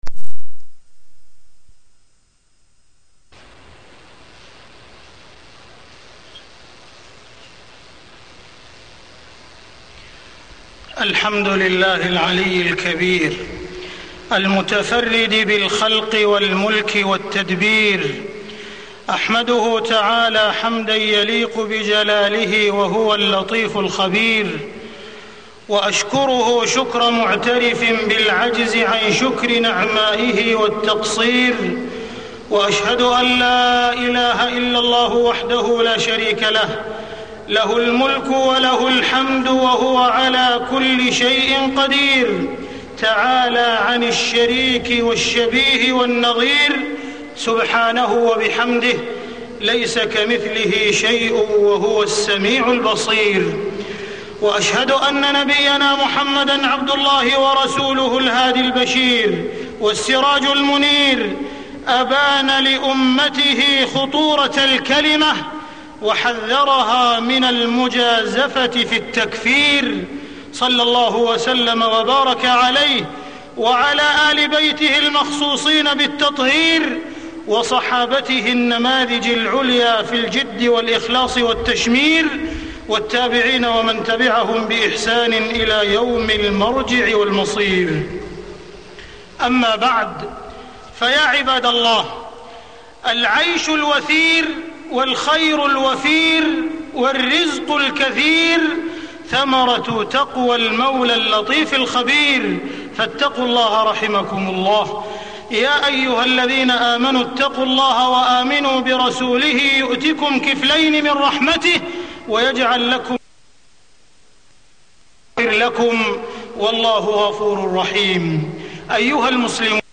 تاريخ النشر ٢٥ ربيع الأول ١٤٢٥ هـ المكان: المسجد الحرام الشيخ: معالي الشيخ أ.د. عبدالرحمن بن عبدالعزيز السديس معالي الشيخ أ.د. عبدالرحمن بن عبدالعزيز السديس ضوابط التكفير